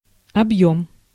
Ääntäminen
US : IPA : [ɪk.ˈstɛnt]